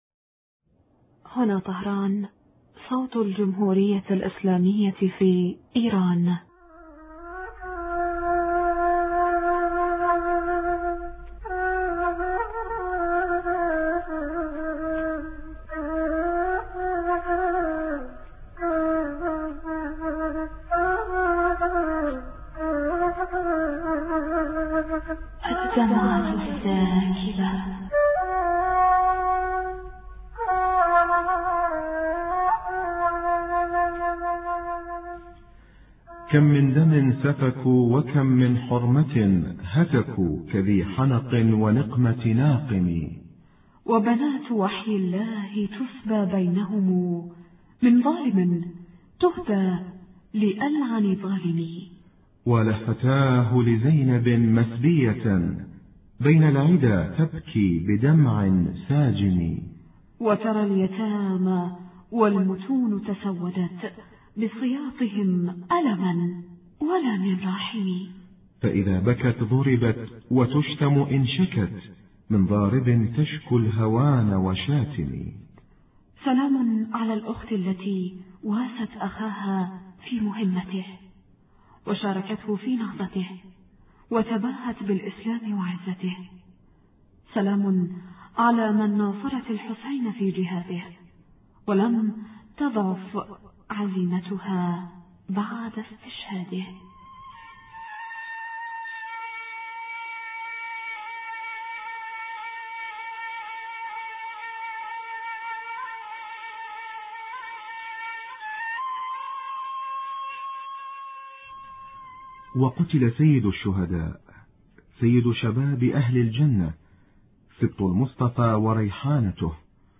******* وللعقيلة زينب سلام الله عليها كلمة خالدة يوم عاشوراء نتعرف على بعض دلالاتها في الاتصال الهاتفي مع سماحة الشيخ علي الكوراني .